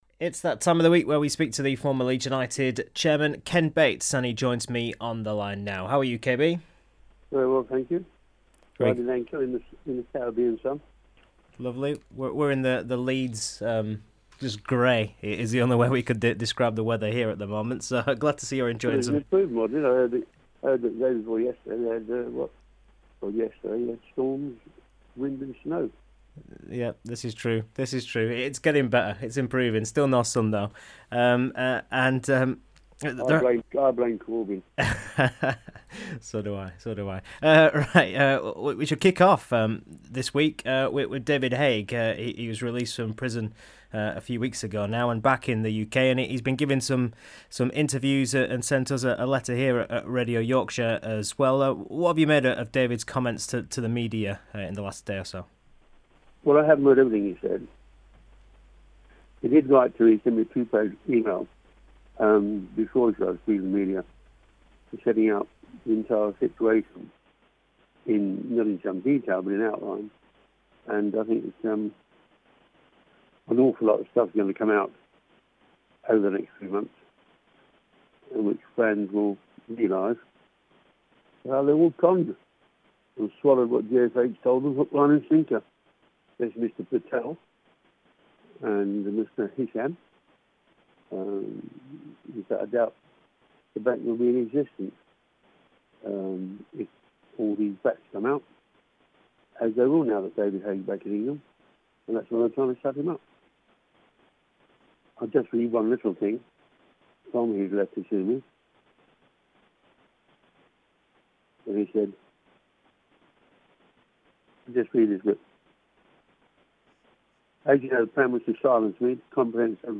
Ken Bates interview 7 April